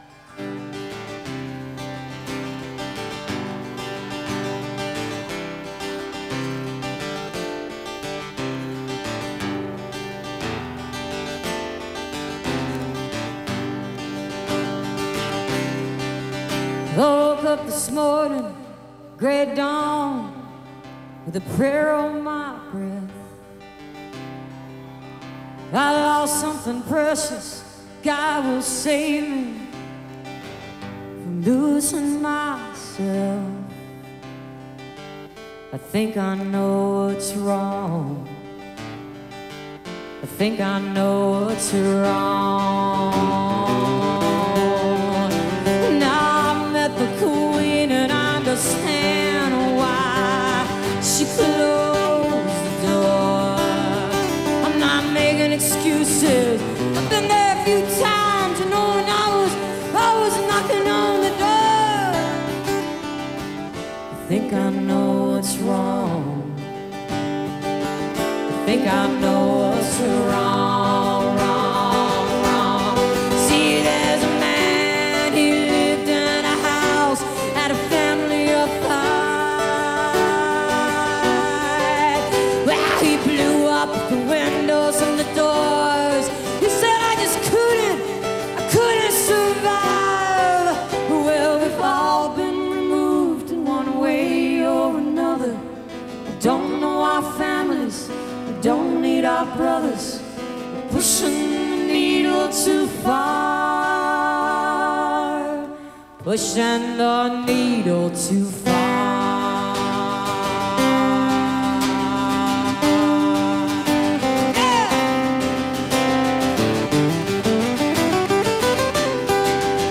1993-08-21 autzen stadium - eugene, oregon